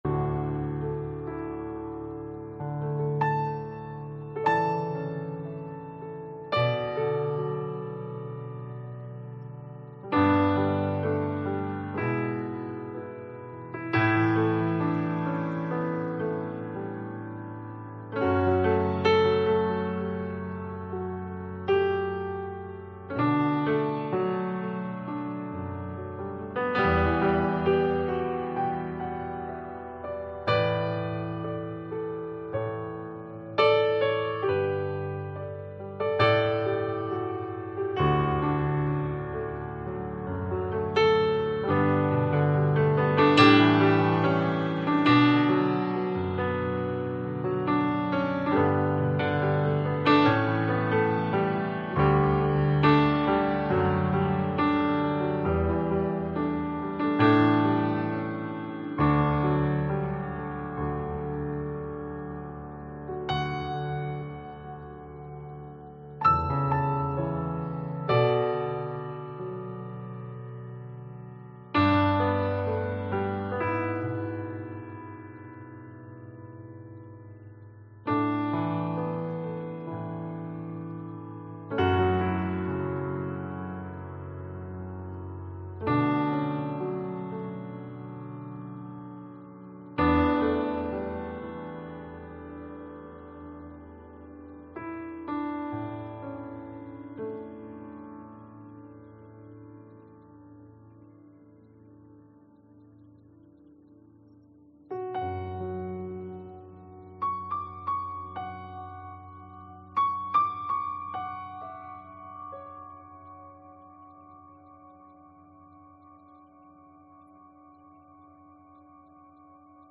Soaking Prayer and Worship (audio) January 6, 2026